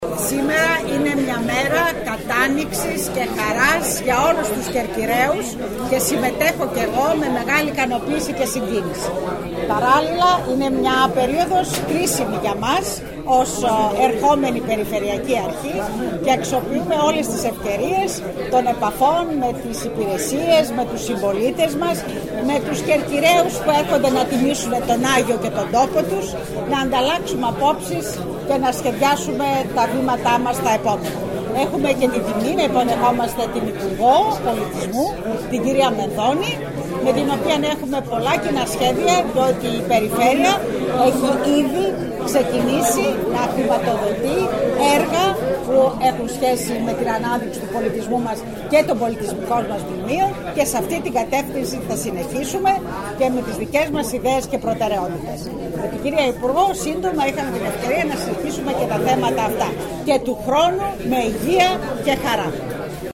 Κέρκυρα: Δηλώσεις πολιτικών και αυτοδιοικητικών (audio)